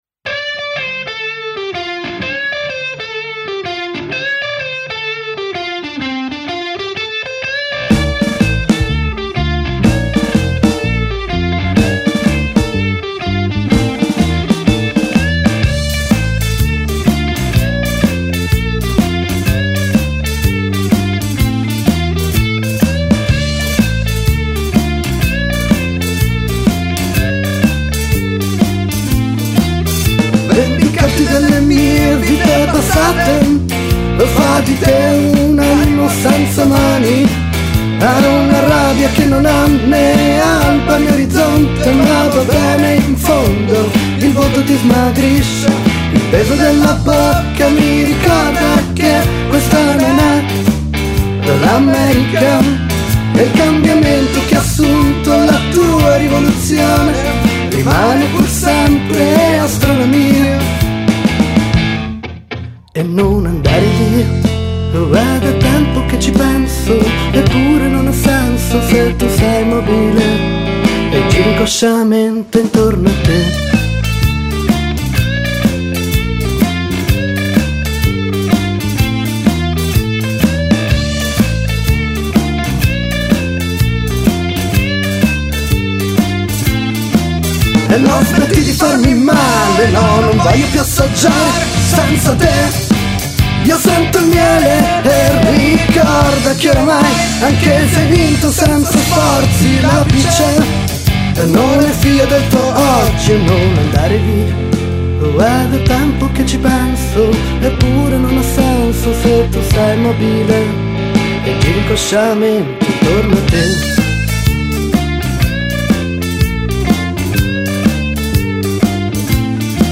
Siamo un gruppo pop rock e alternative rock.
voce e basso
seconda voce e batteria
chitarra